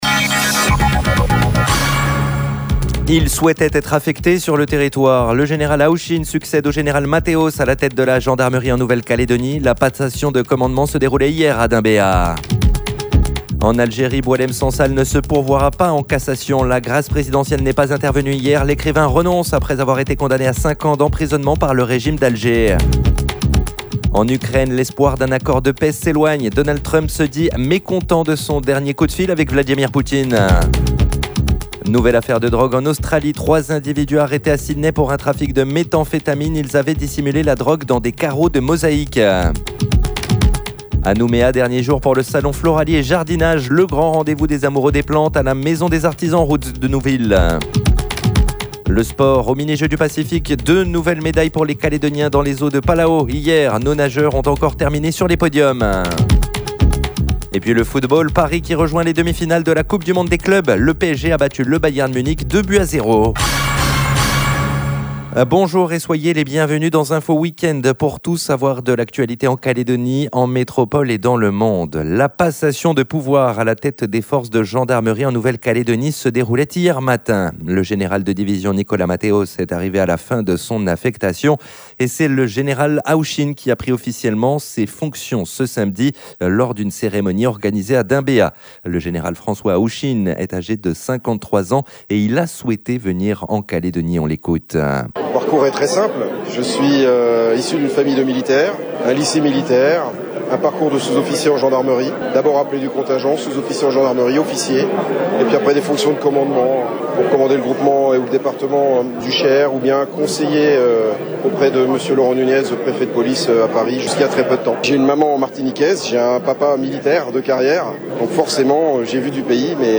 JOURNAL : INFO WEEK-END DIMANCHE MATIN 06/07/25